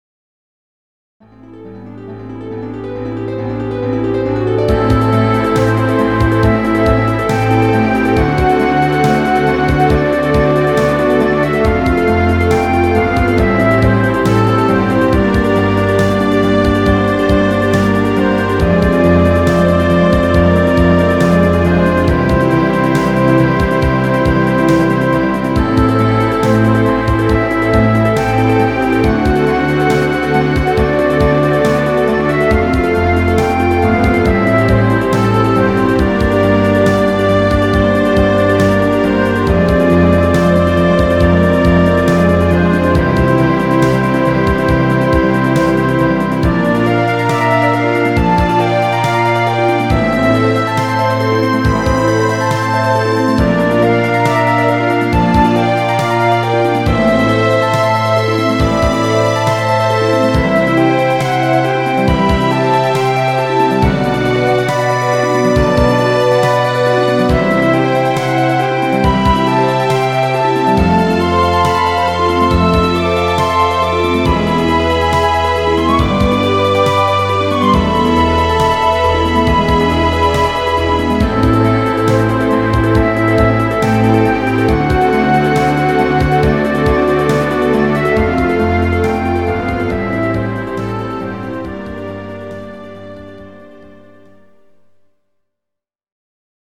GS音源   2008.06